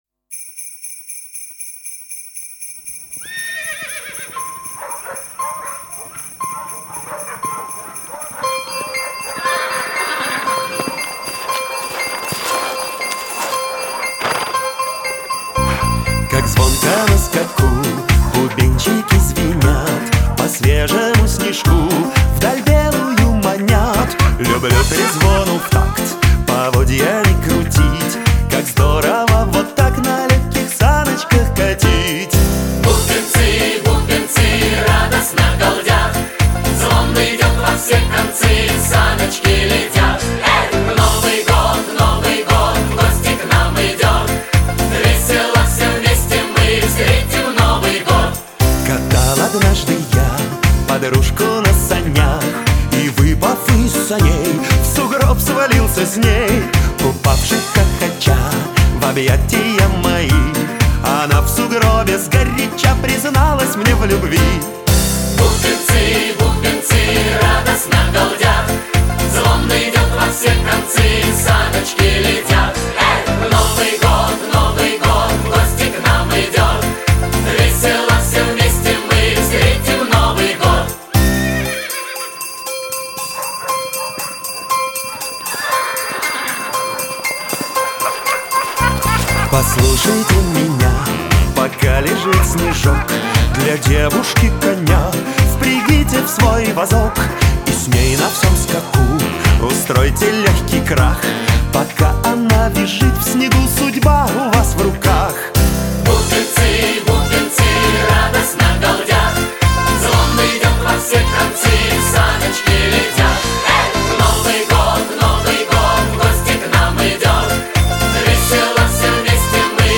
Звук новогодней песни